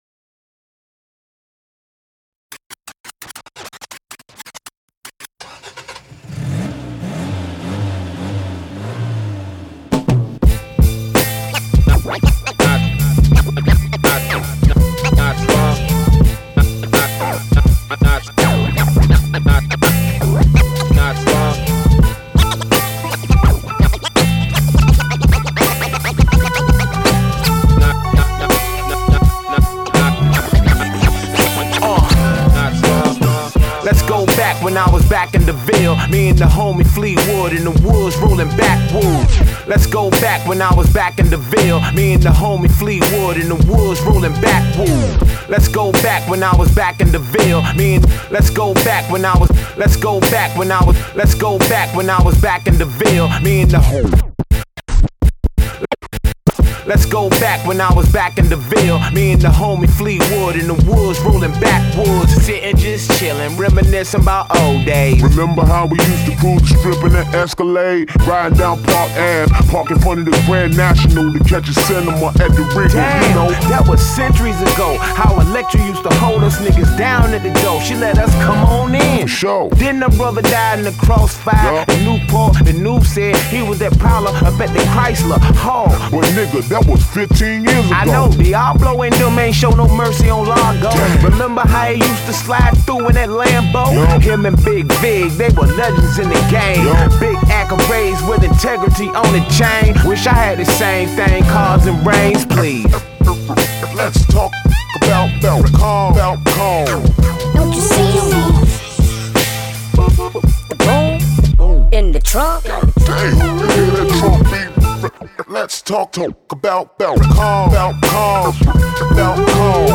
ソウルフルでバウンシーなビートにユニークかつ高度なサンプリングアレンジテクニック、そして重圧なベースライン。